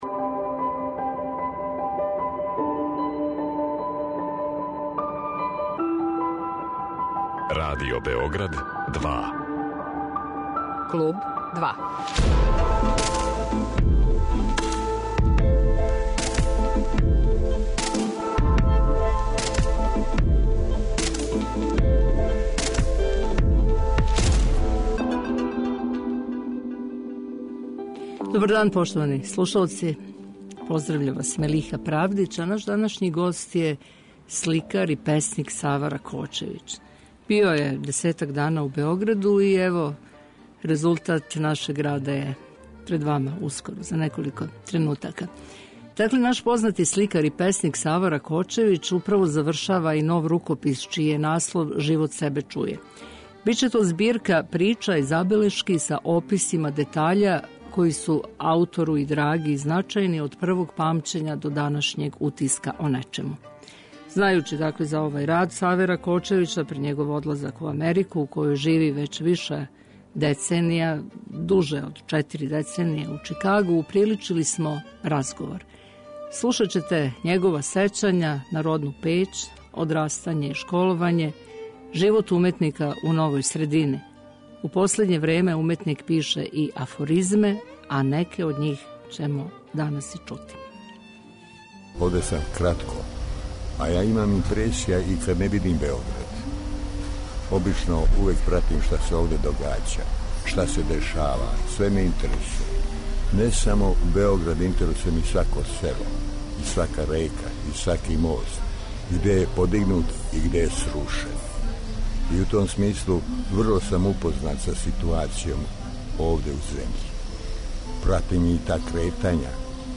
уприличили смо разговор. Слушаћете његова сећања на родну Пећ, одрастање и школовање, живот уметника у новој средини.